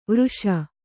♪ vřṣa